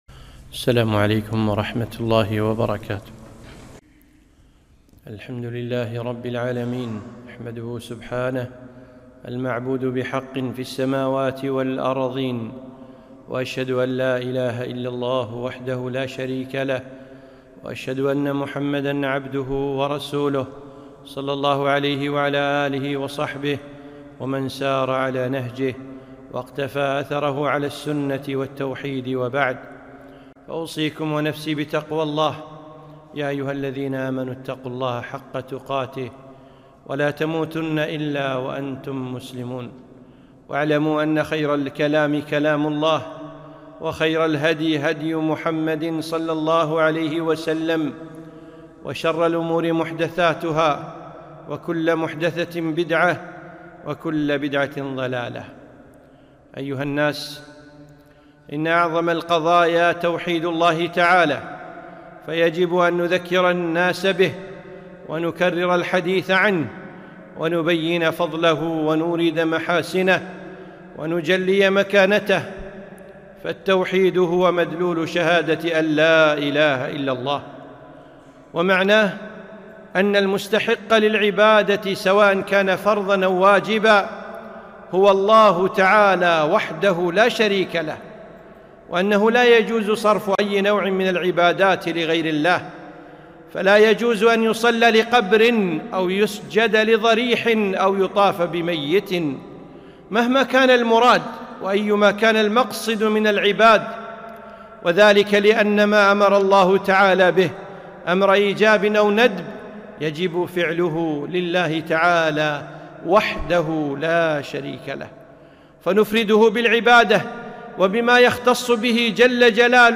خطبة -- أهمية التوحيد وفضله